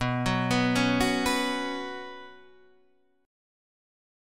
B Suspended 2nd